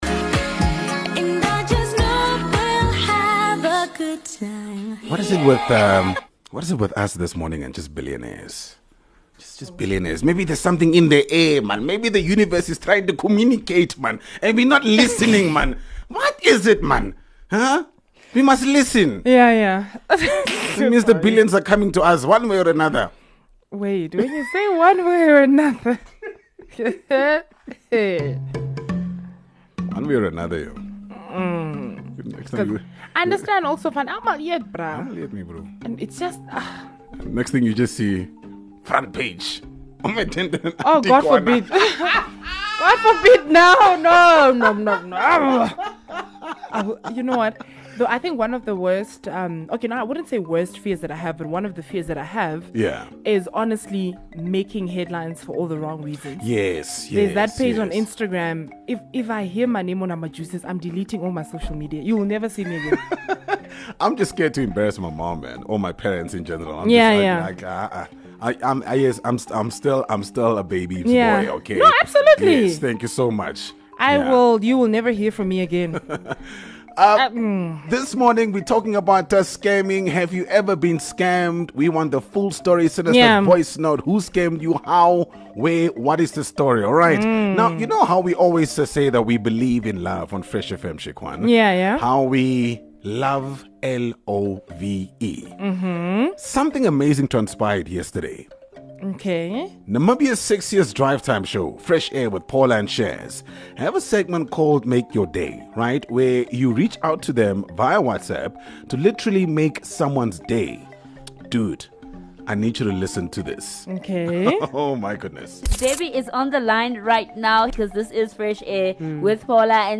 7 Mar Someone proposed LIVE ON RADIO!